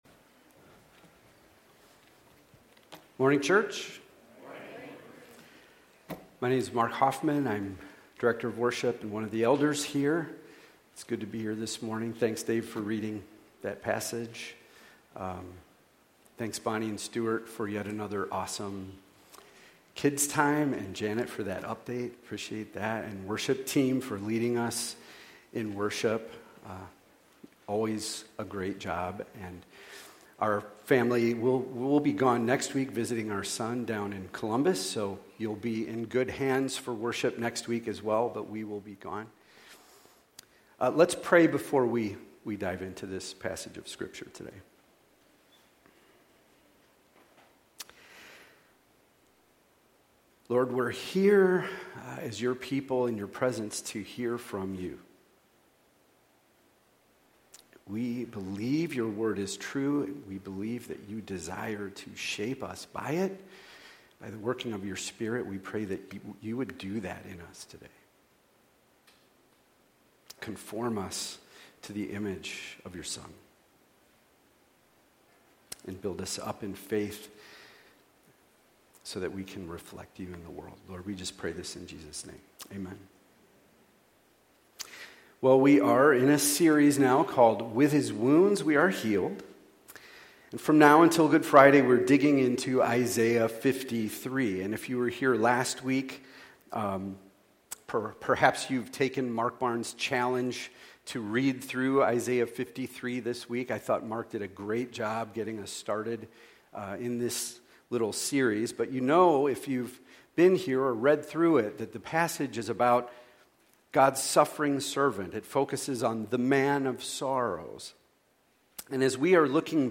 Sermons | Meadows Christian Fellowship